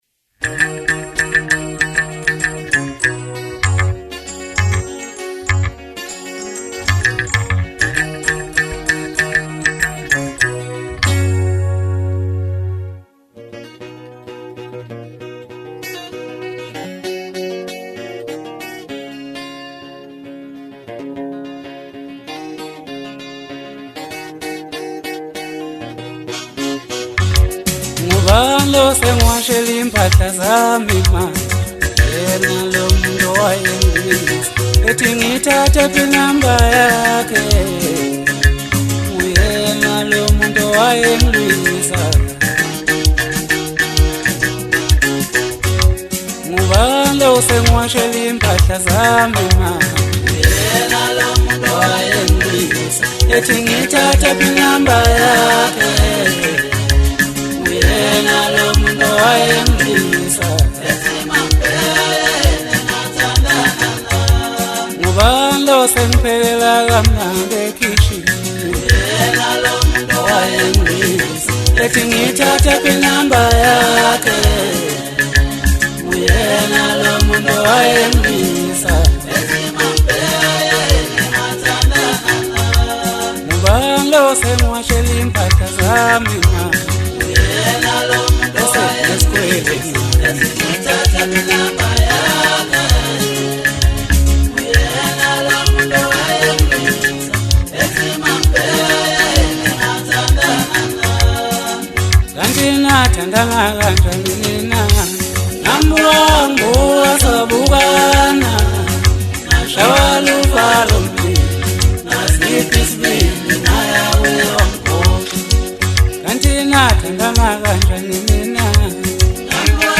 Genre : Masakndi